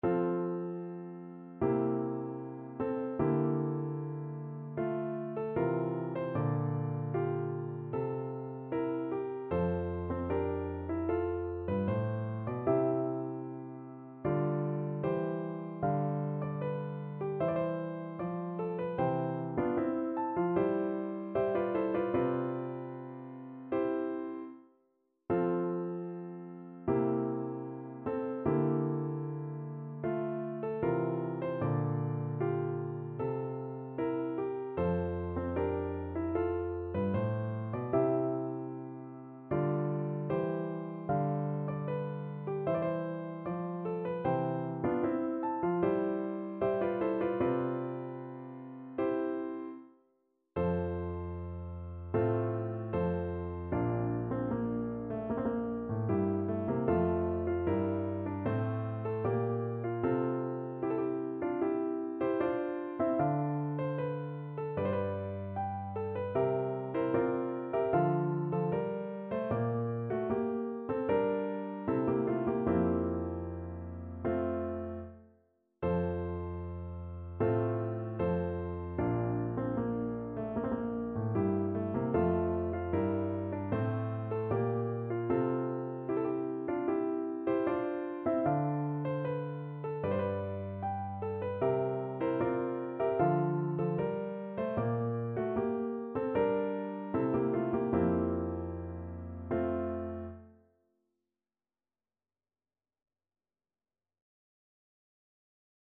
Piano version
No parts available for this pieces as it is for solo piano.
Andante =76
4/4 (View more 4/4 Music)
Classical (View more Classical Piano Music)